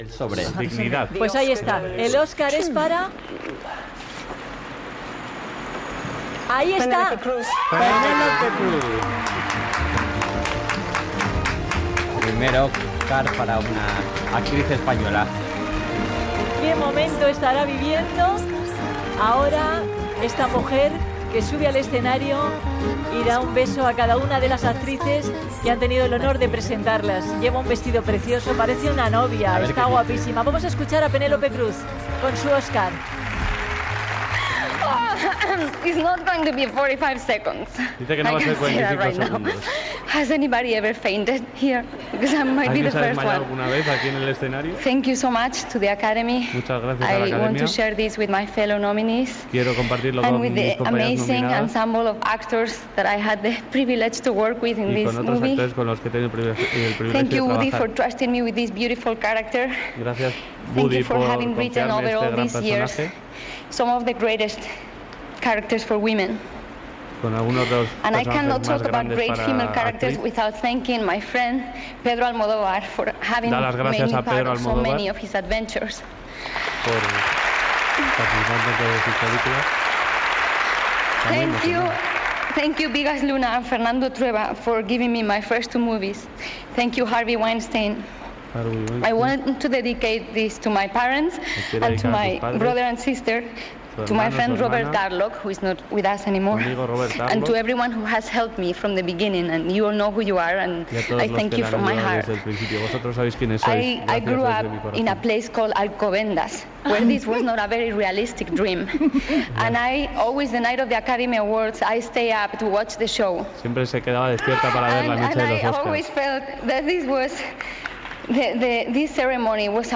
Especial transmissió de la cerimònia de lliurament dels Oscar. Millor actriu de repartiment a Penélope Cruz, qui fa un parlament d'agraïment.